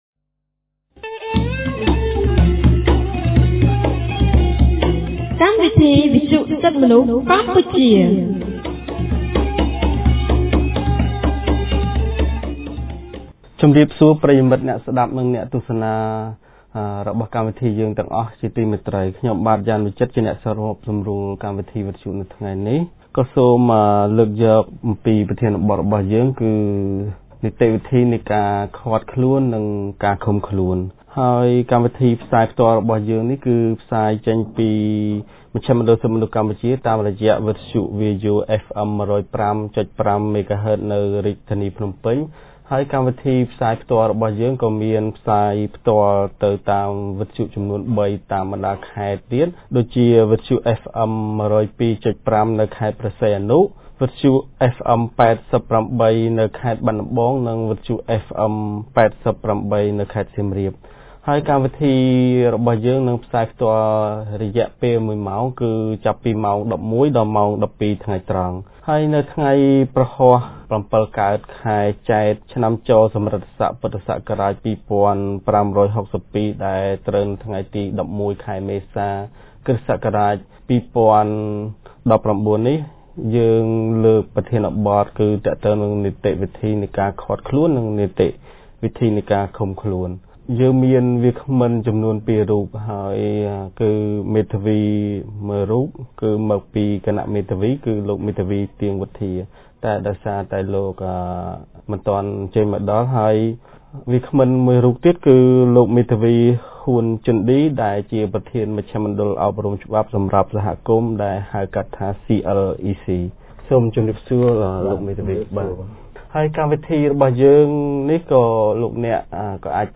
កាលពីថ្ងៃព្រហស្បតិ៍ ថ្ងៃទី១១ ខែមេសា ឆ្នាំ២០១៩ គម្រាងសិទ្ធិទទួលបានការជំនុំជម្រះដោយយុត្តិធម៌នៃមជ្ឈមណ្ឌលសិទ្ធិមនុស្សកម្ពុជា បានរៀបចំកម្មវិធីវិទ្យុក្រោមប្រធានបទស្តីពី នីតិវិធីនៃការឃាត់ខ្លួន និងឃុំខ្លួន។